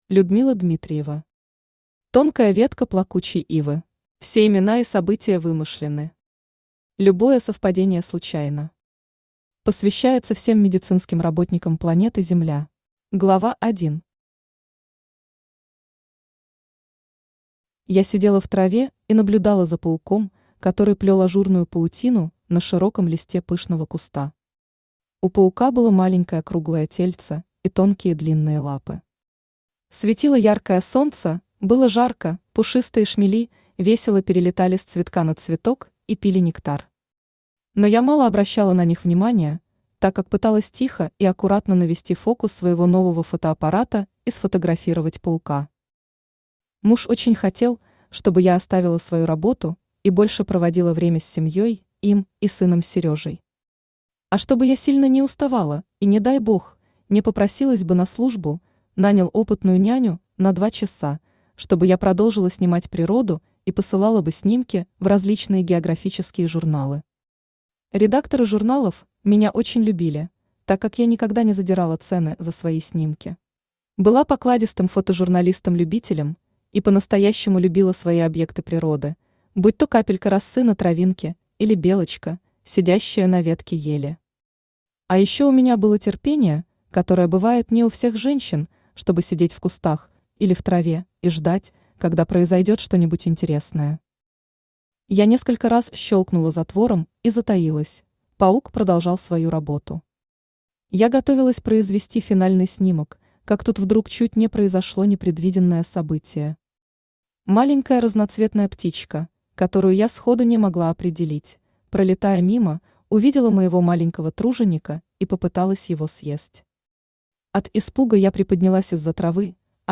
Aудиокнига Тонкая ветка плакучей ивы Автор Людмила Вячеславовна Дмитриева Читает аудиокнигу Авточтец ЛитРес.